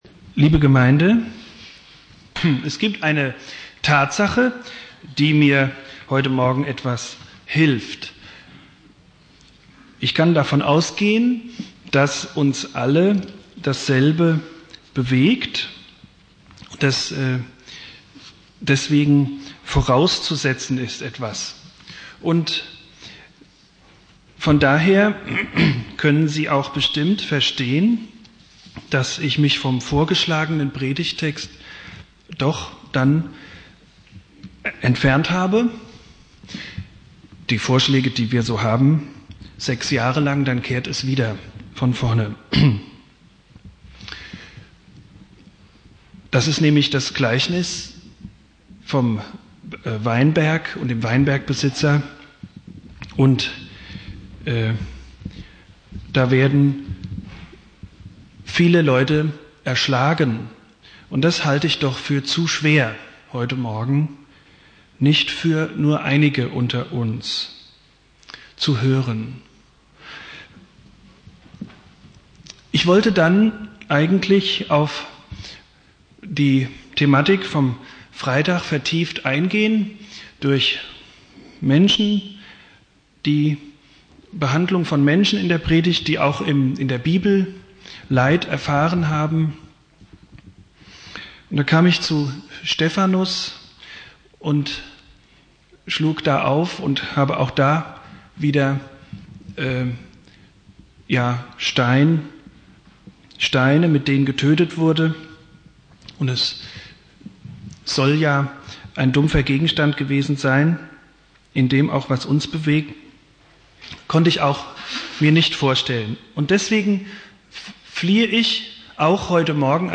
"Hiob" Predigtreihe: Themenpredigten Dauer